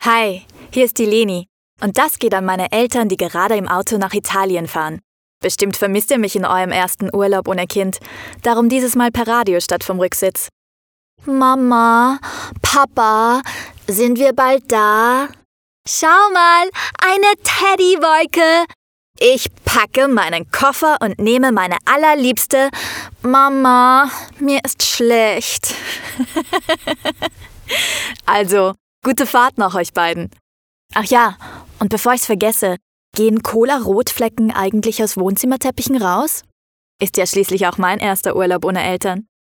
Speaker
Modersmålstalare